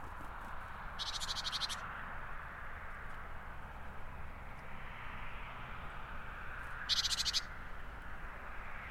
Trying out a Christmas present to myself: a portable sound recorder 🙂.
Today I went outside into the garden (and by doing so chased away long-tailed tits without the recorder on...) and started my new journey.
With a tiny first result so far 🙂 - A great Tit.